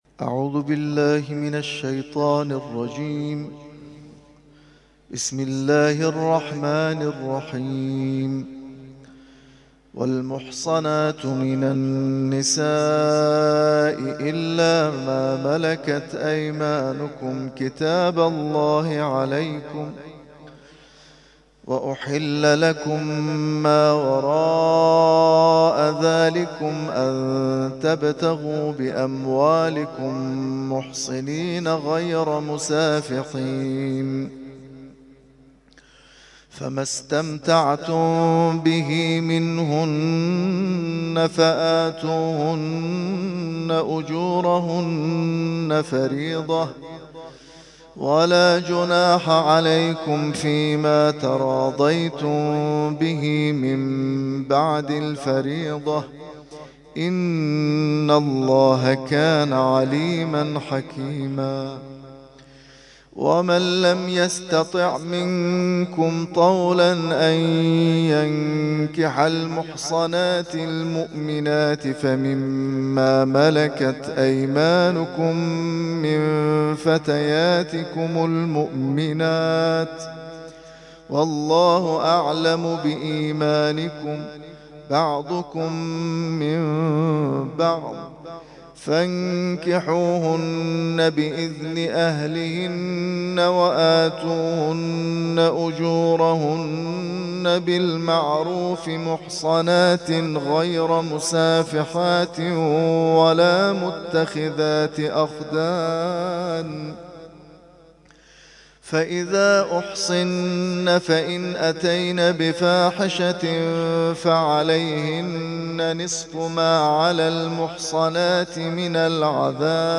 ترتیل خوانی جزء ۵ قرآن کریم در سال ۱۳۹۶